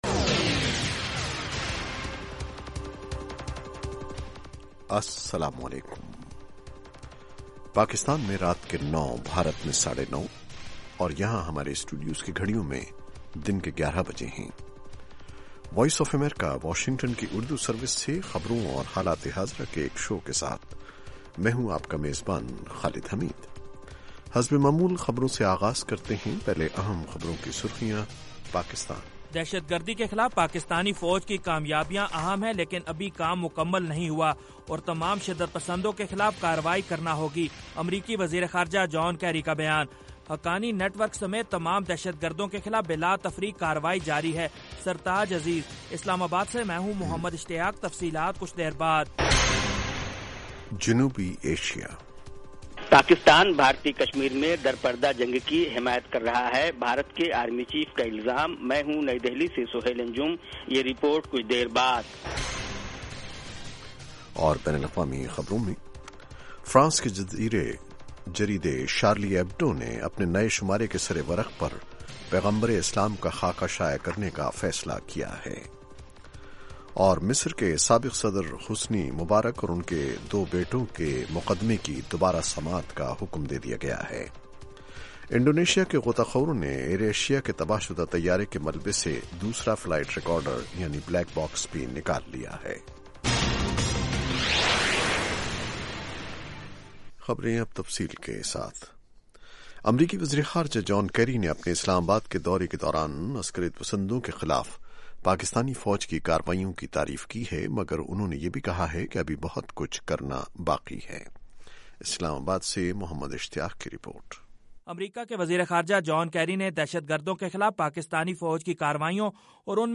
دن بھر کی اہم خبریں اور پاکستان اور بھارت سے ہمارے نمائندوں کی رپورٹیں۔ اس کے علاوہ انٹرویو، صحت، ادب و فن، کھیل، سائنس اور ٹیکنالوجی اور دوسرے موضوعات کا احاطہ۔